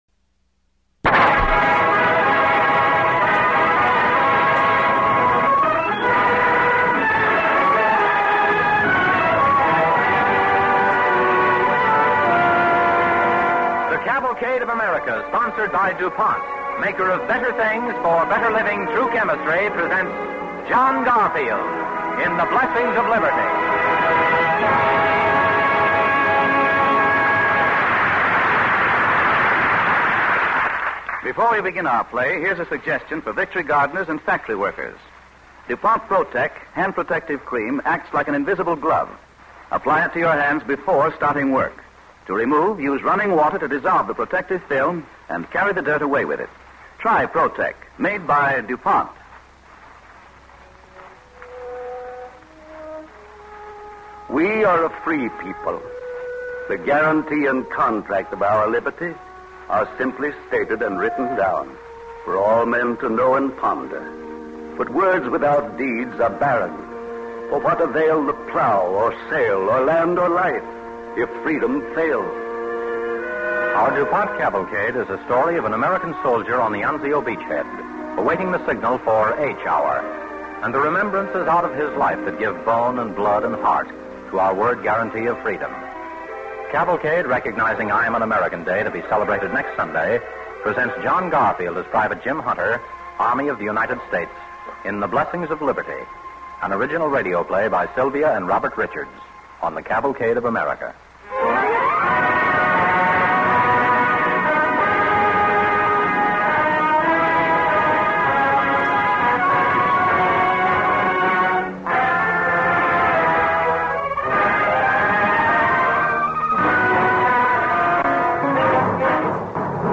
The Blessings of Liberty, starring John Garfield and Francis X. Bushman